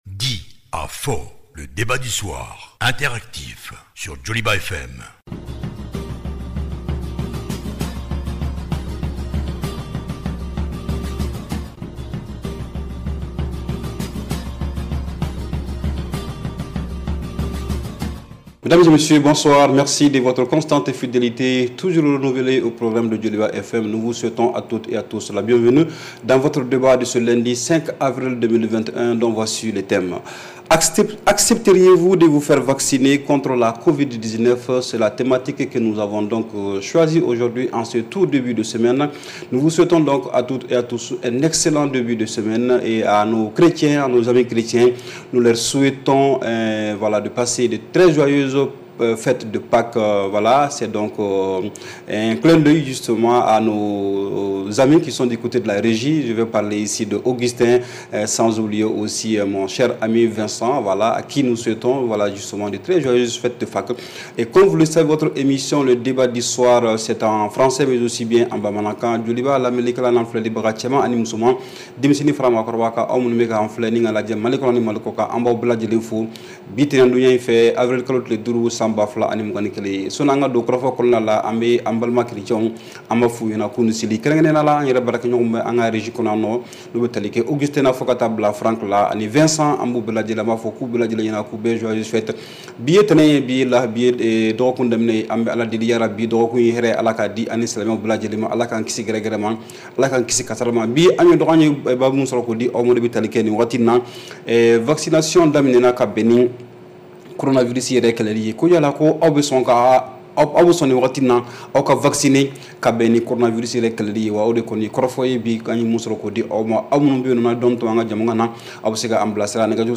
REPLAY 05/04 – « DIS ! » Le Débat Interactif du Soir